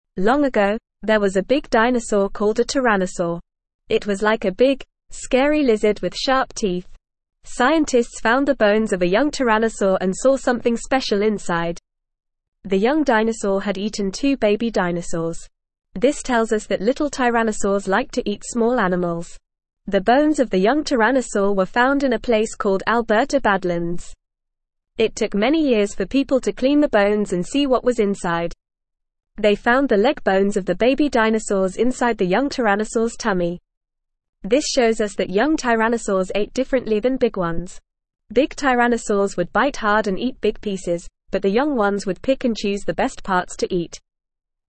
Fast
English-Newsroom-Beginner-FAST-Reading-Young-Tyrannosaurs-Ate-Baby-Dinosaurs-Scientists-Discover.mp3